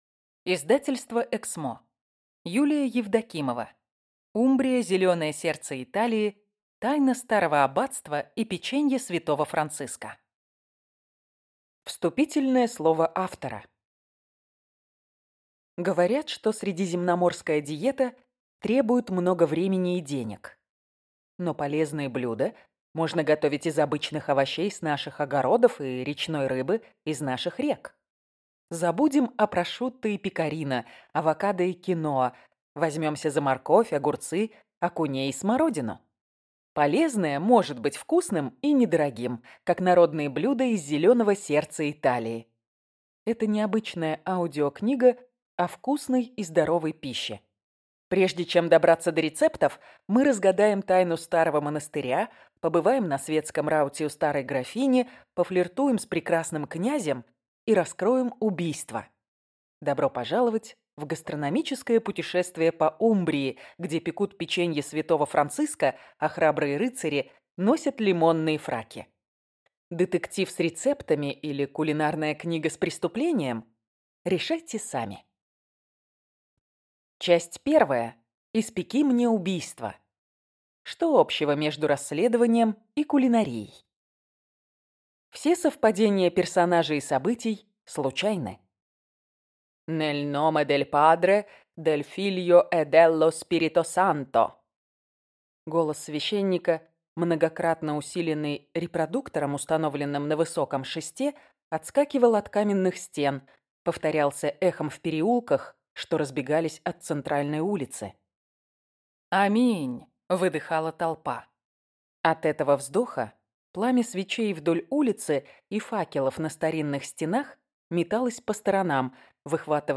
Аудиокнига Умбрия – зеленое сердце Италии. Тайна старого аббатства и печенье святого Франциска | Библиотека аудиокниг